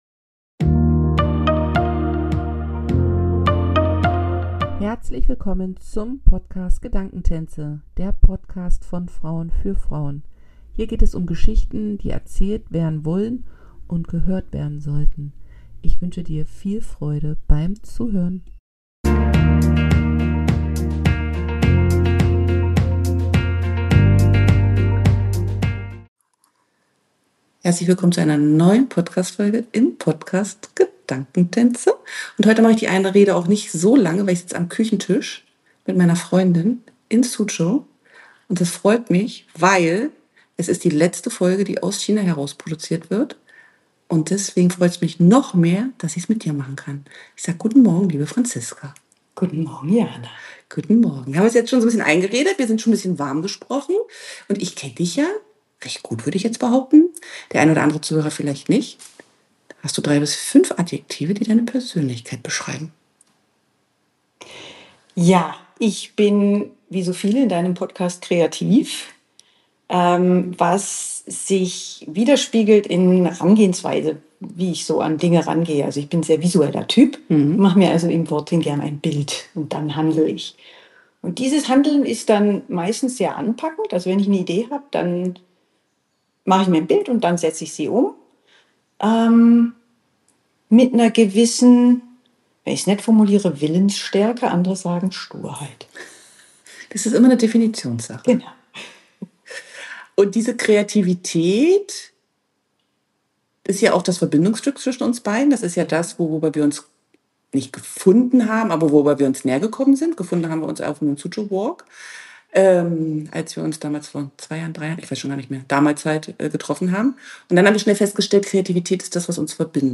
Für mich ist diese Episode ganz besonders, da wir uns direkt gegenüber saßen und es ein toller Abschluss ist, für meinen Podcast gesendet aus Suzhou.